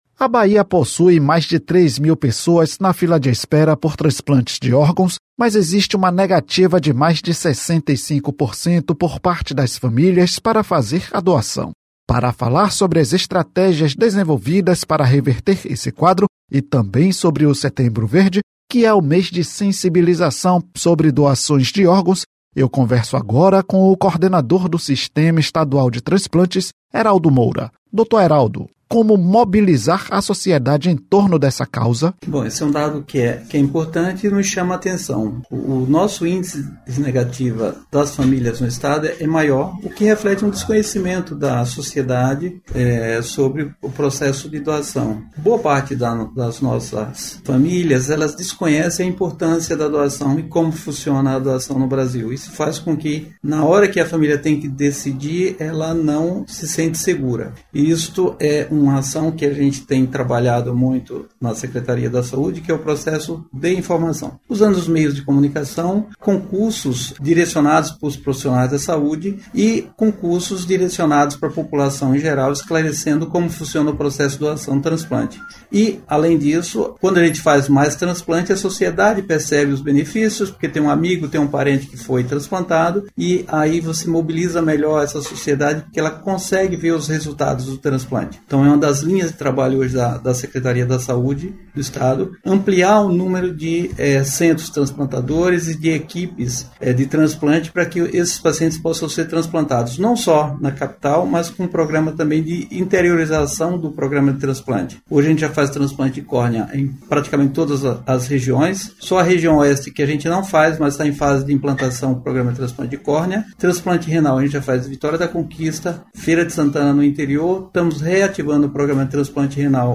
ENTREVISTA | Doação de órgãos na Bahia tem negativa familiar superior a 65%